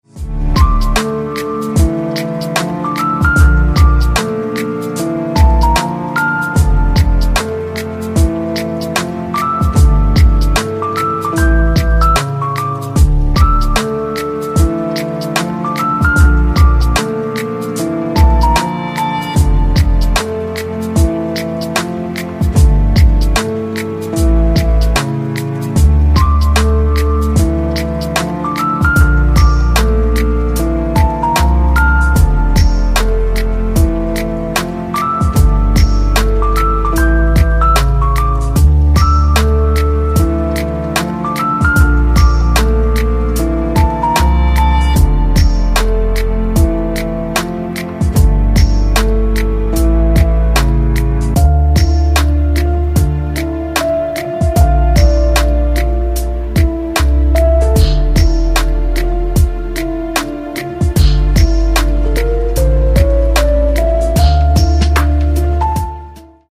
Chillout